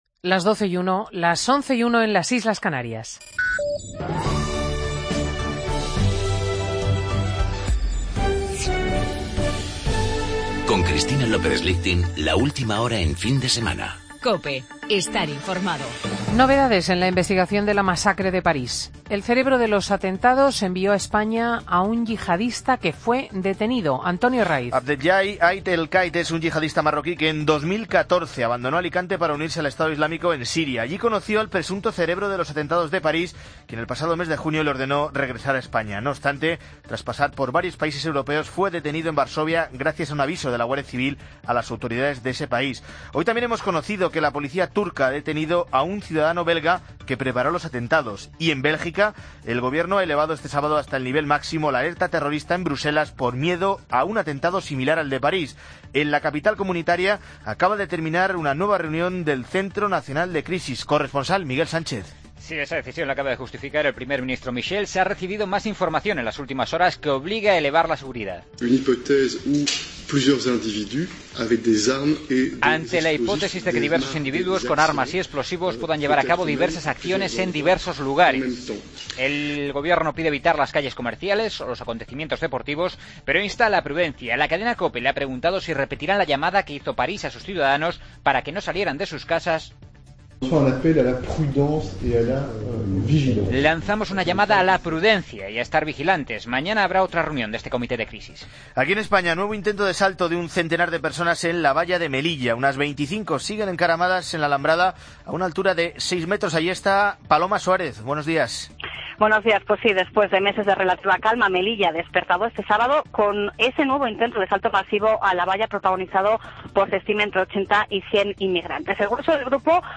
Noticias de las 12h del sábado 21 de noviembre de 2015